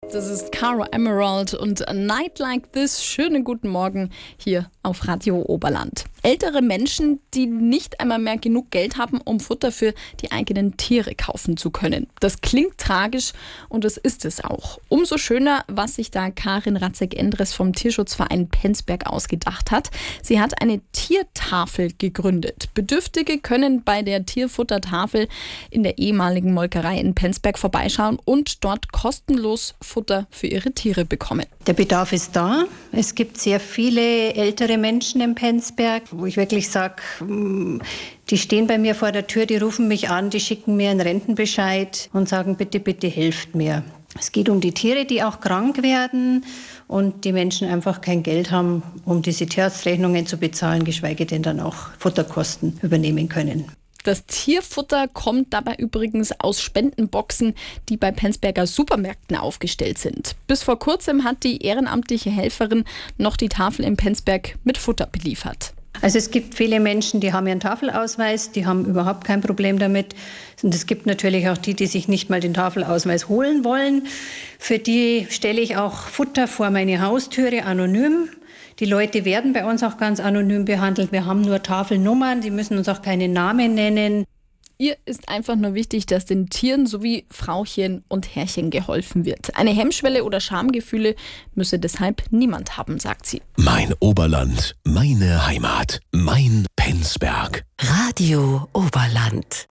Tierschutz Penzberg Interview mit Radio Oberland zur Tiertafel
Radio Oberland hat einen Beitrag zur Tiertafel und deren Hintergründe gesendet, hier für Sie zum Nachhören in zwei Teilen!